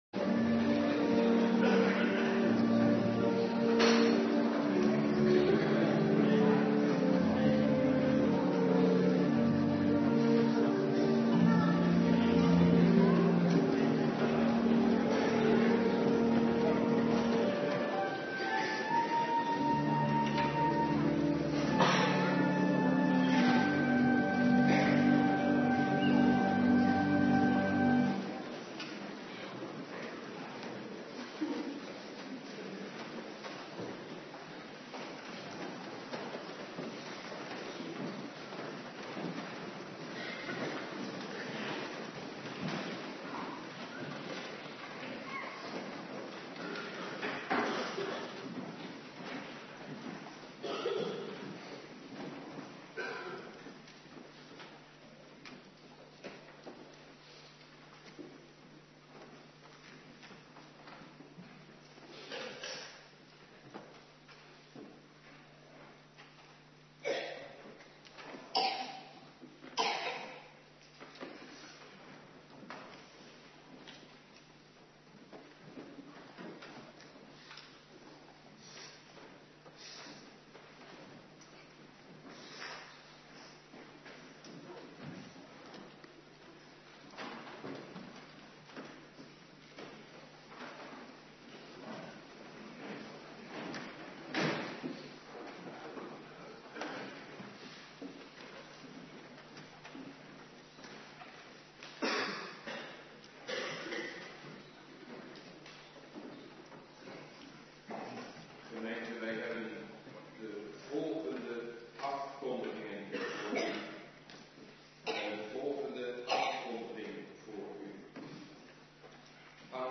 Morgendienst